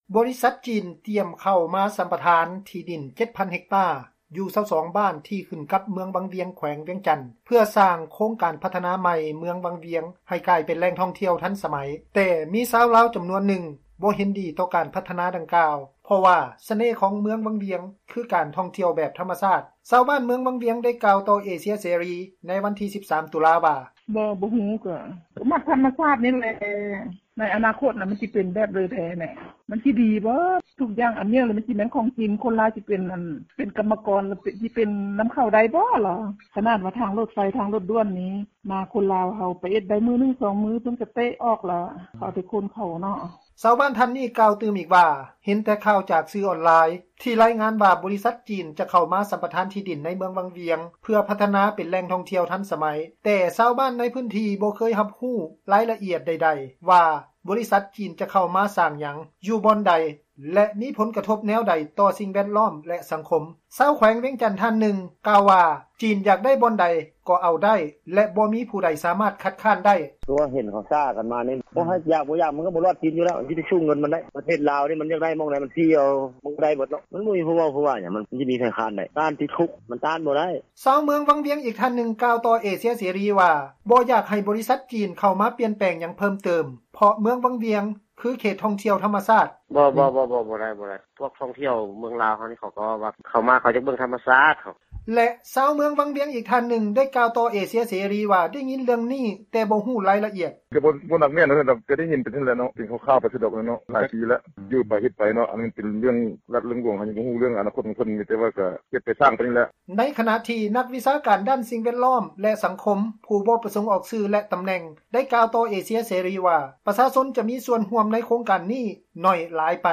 ບໍຣິສັດຈີນ ຕຽມເຂົ້າມາສໍາປະທານດິນ 7,000 ເຮັກຕາ ຢູ່ 22 ບ້ານທີ່ຂຶ້ນກັບ ເມືອງວັງວຽງ ແຂວງວຽງຈັນ ເພື່ອສ້າງໂຄງການພັທນາໃໝ່ ເມືອງວັງວຽງ ໃຫ້ກາຍເປັນແຫຼ່ງທ່ອງທ່ຽວ ທັນສະໄໝ ແຕ່ຊາວລາວ ຈໍານວນນຶ່ງ ບໍ່ເຫັນດີຕໍ່ການພັທນາ ດັ່ງກ່າວ ເພາະວ່າສະເໜ່ ຂອງ ເມືອງວັງວຽງ ຄື ການທ່ອງທ່ຽວແບບທໍາມະຊາດ. ຊາວບ້ານເມືອງວັງວຽງ ໄດ້ກ່າວຕໍ່ເອເຊັຽເສຣີ ໃນວັນທີ 13 ຕຸລາ ວ່າ:
ຊາວເມືອງວັງວຽງ ອີກທ່ານນຶ່ງ ກ່າວຕໍ່ເອເຊັຽເສຣີ ວ່າ ບໍ່ຢາກໃຫ້ບໍຣິສັດຈີນເຂົ້າມາ ປ່ຽນແປງຫຍັງເພີ່ມເຕີມ ເພາະເມືອງວັງວຽງ ຄືເຂດ ທ່ອງທ່ຽວທໍາມະຊາດ: